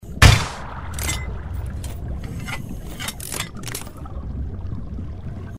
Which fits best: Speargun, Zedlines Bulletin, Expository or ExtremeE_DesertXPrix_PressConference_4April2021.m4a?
Speargun